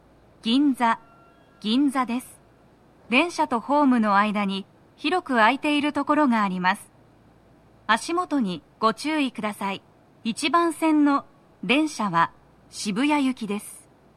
スピーカー種類 TOA天井型
🎵到着放送
足元注意喚起放送が付帯されており、多少の粘りが必要です。